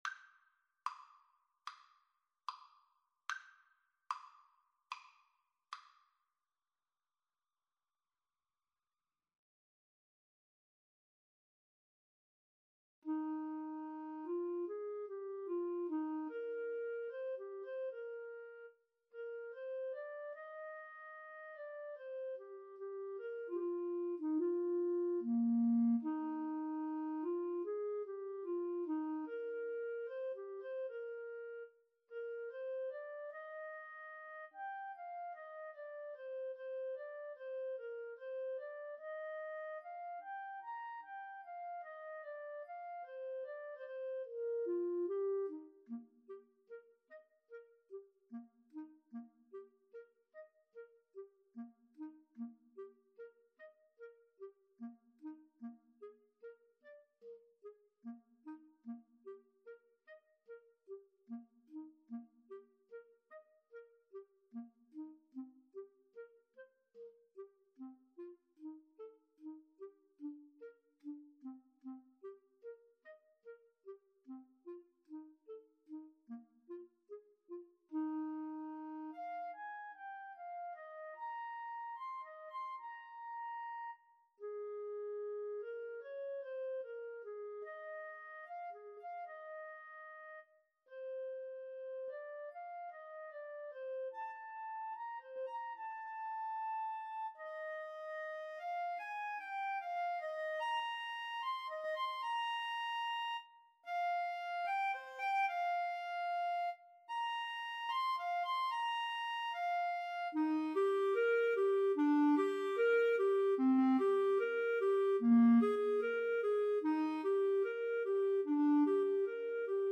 Eb major (Sounding Pitch) F major (Clarinet in Bb) (View more Eb major Music for Clarinet Duet )
Andantino quasi allegretto ( = 74) (View more music marked Andantino)
Classical (View more Classical Clarinet Duet Music)